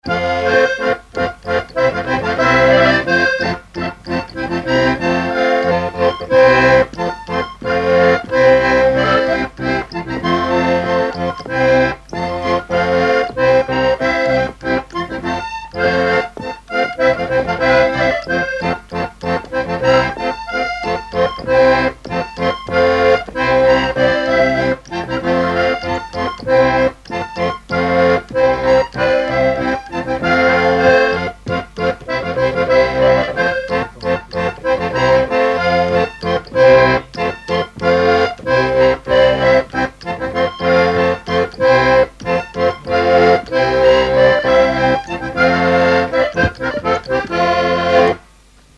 instrumentaux à l'accordéon diatonique
Pièce musicale inédite